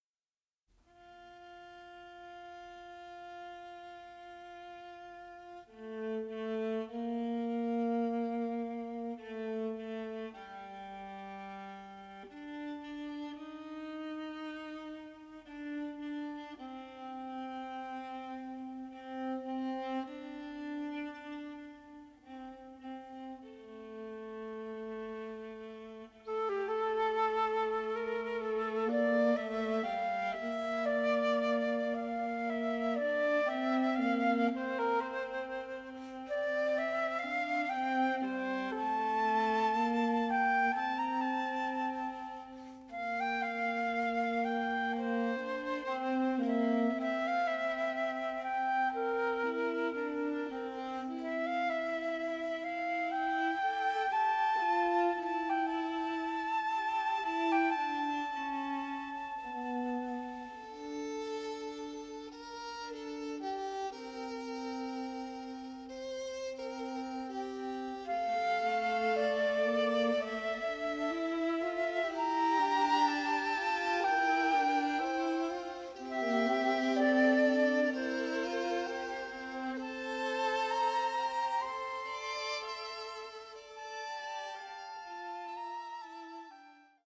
composed for full orchestra.
Violin, Viola And Flute